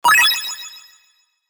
8ビットサウンドが心地よく耳に響き、次第に激しい爆風音が炸裂。